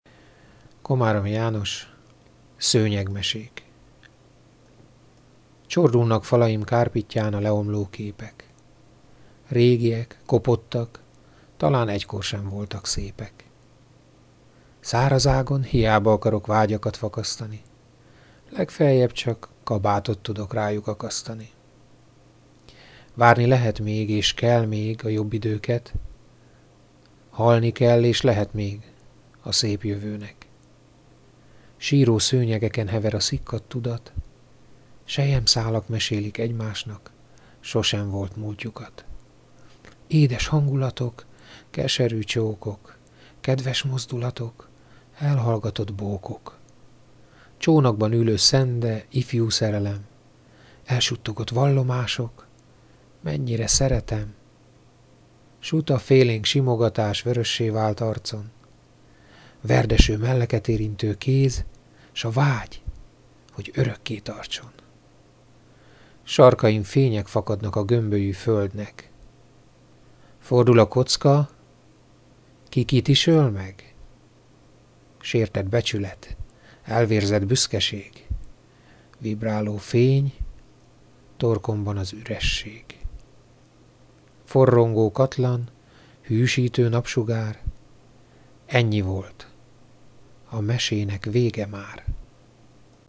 Egyszer azonban gondoltam egyet és elmondtam néhány versemet.